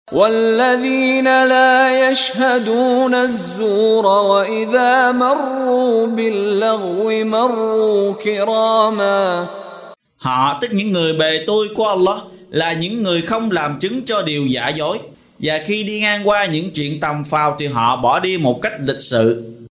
Đọc ý nghĩa nội dung chương Al-Furqan bằng tiếng Việt có đính kèm giọng xướng đọc Qur’an